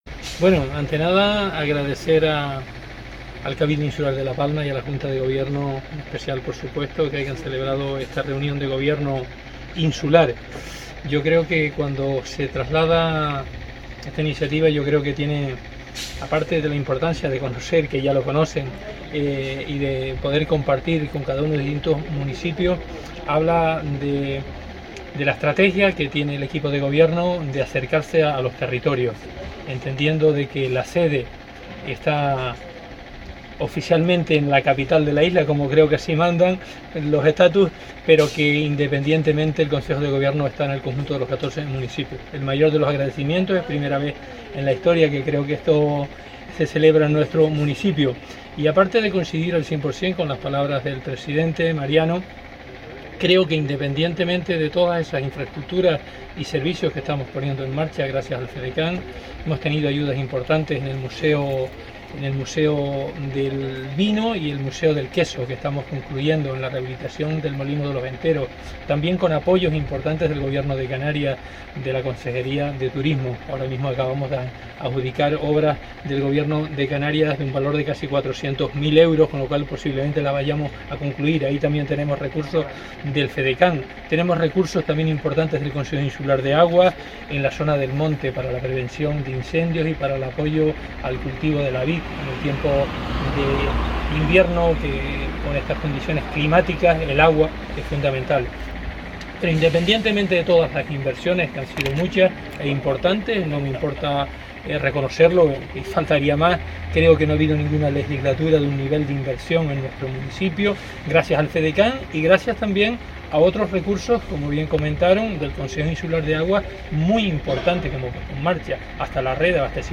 Declaraciones audio Vicente Rodríguez.mp3